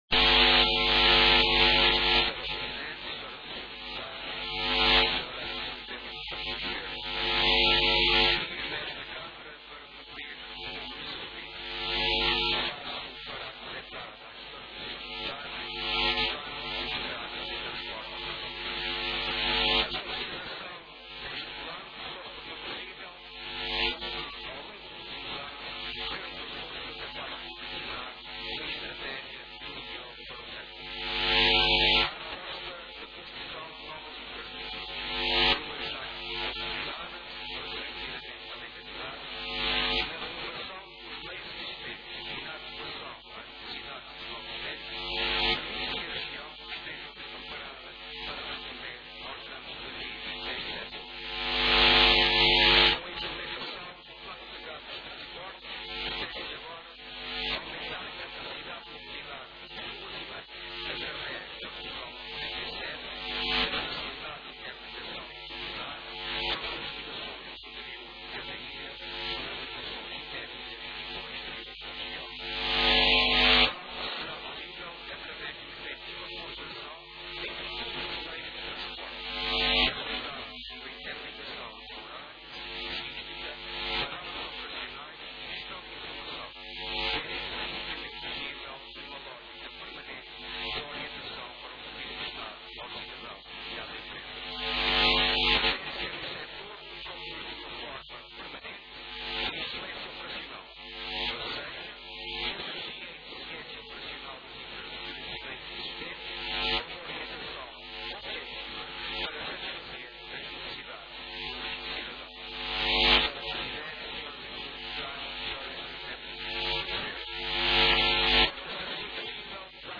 Notícias dos Açores: Intervenção do Secretário Regional do Turismo e Transportes